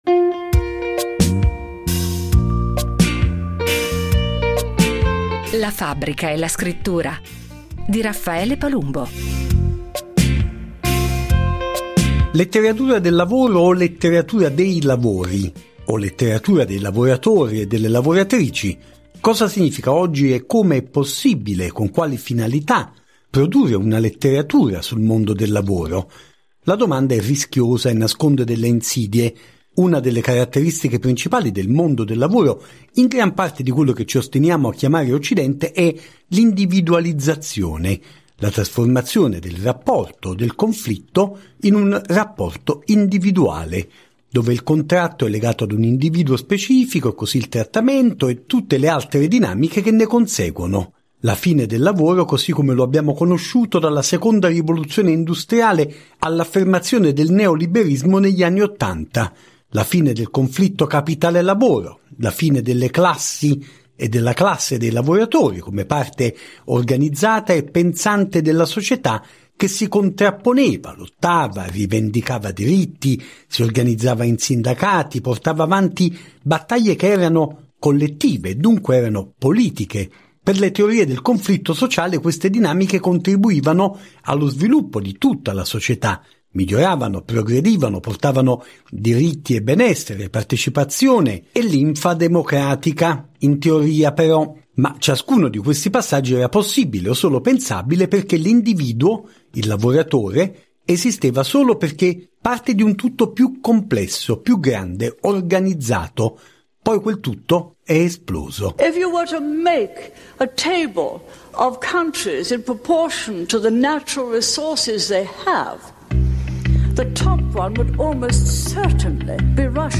ha raccolto le voci e le testimonianze di scrittori e attivisti che si sono dati appuntamento alla terza edizione del Festival di letteratura working class di Campi Bisenzio, in Toscana.